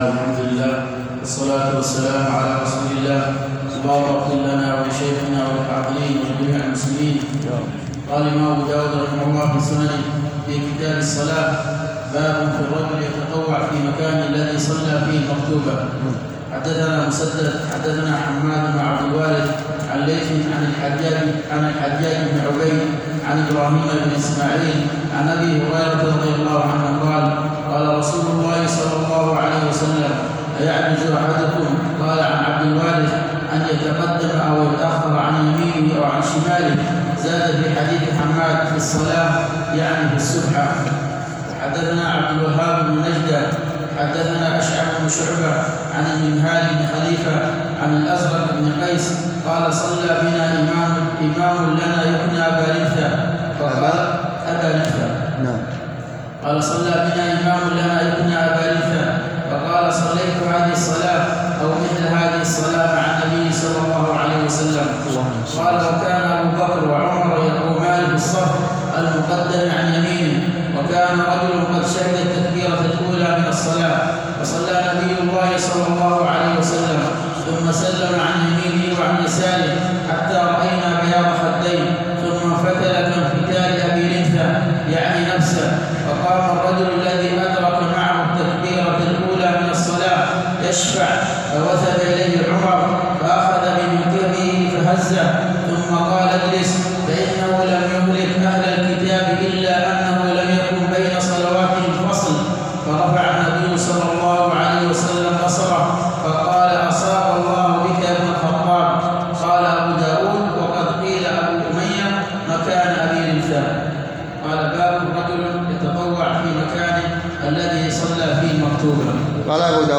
شرح كتاب الصلاة - سنن أبي داود | ٧ جمادى الآخر ١٤٤٤ هـ _ بجامع الدرسي صبيا